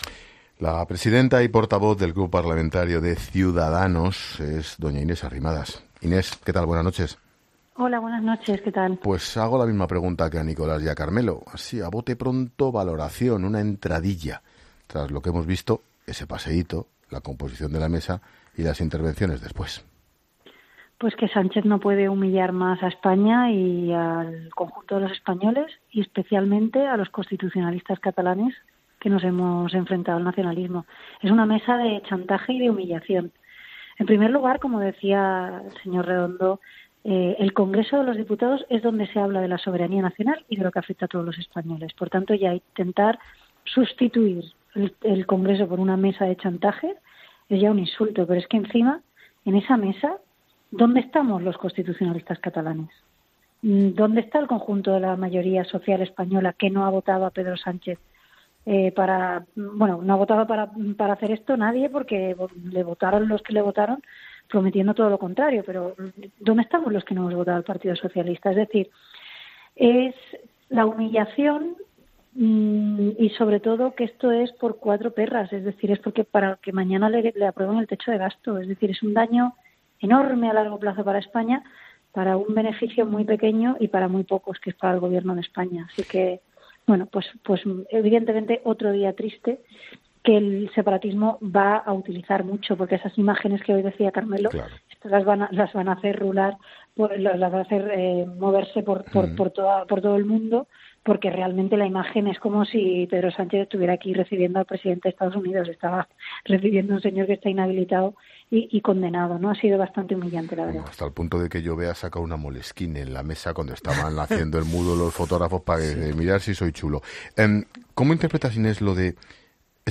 La líder de Ciudadanos pasa por los micrófonos de 'La Linterna' donde es entrevistada por Ángel Expósito